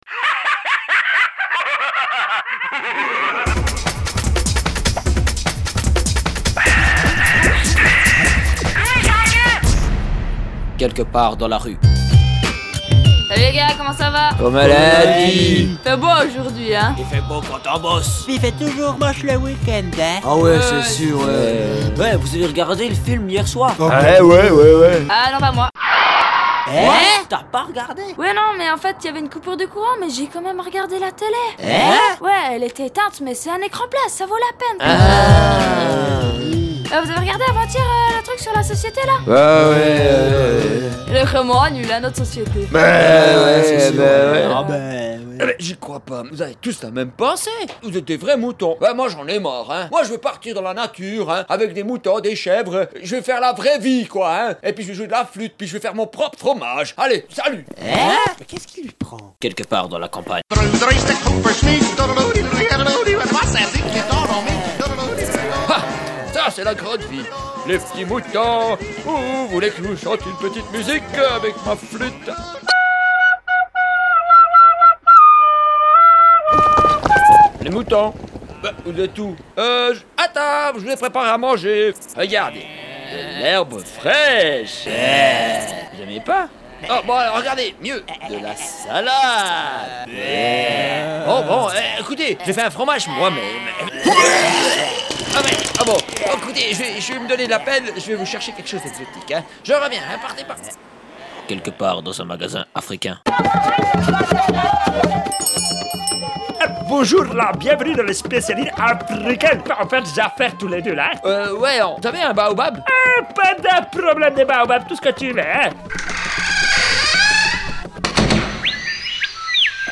Les sketches :